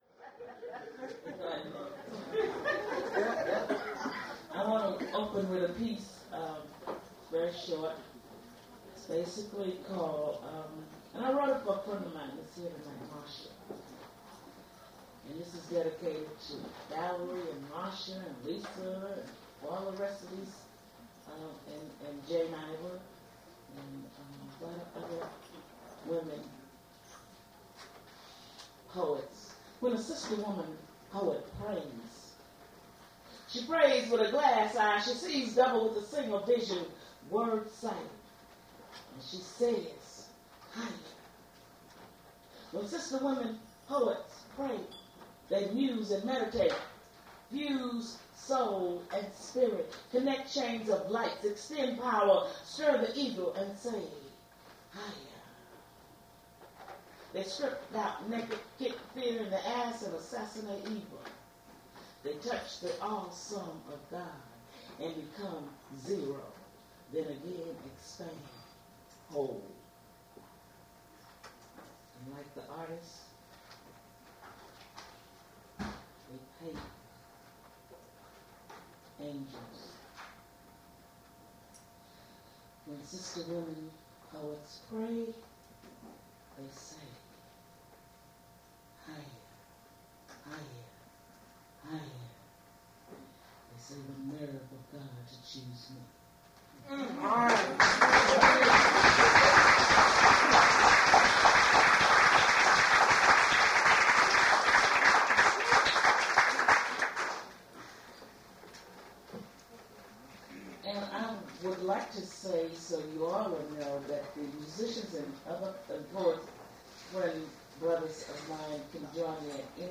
Poetry reading
mp3 edited access file was created from unedited access file which was sourced from preservation WAV file that was generated from original audio cassette. Language English Identifier CASS.734 Series River Styx at Duff's River Styx Archive (MSS127), 1973-2001 Note The quality of the recording is somewhat poor.